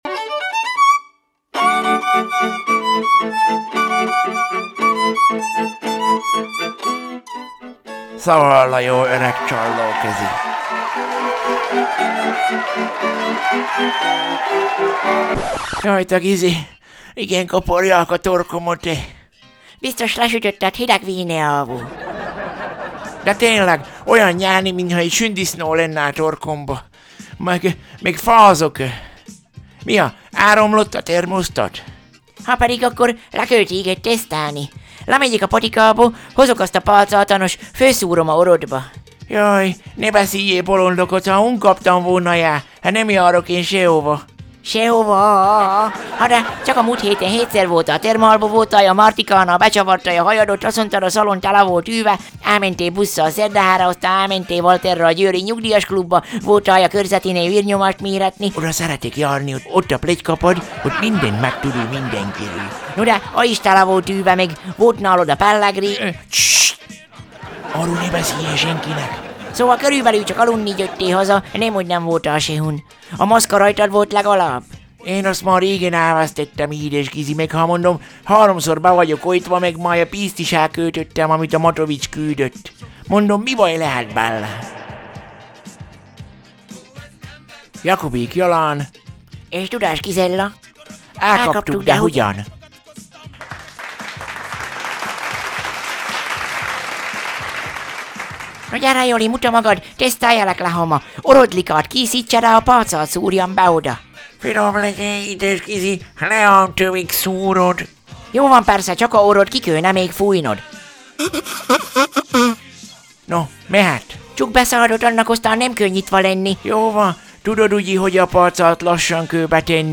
Zene: